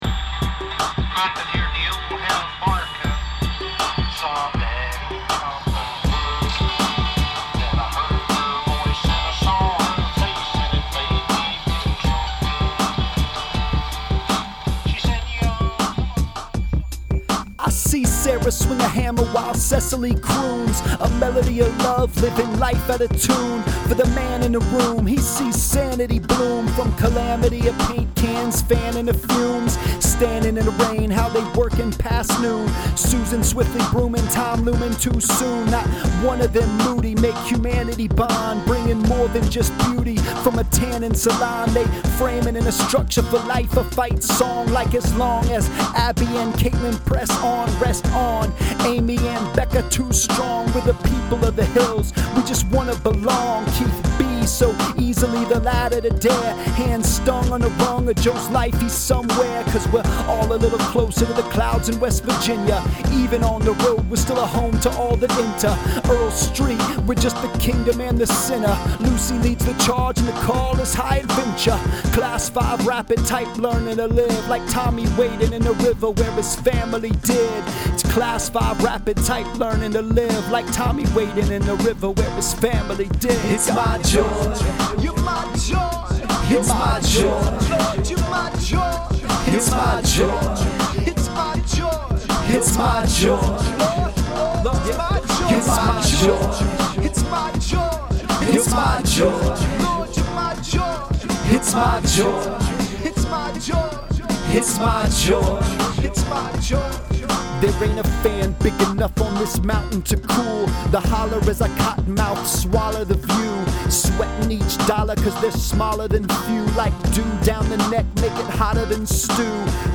Rap news ain’t dead.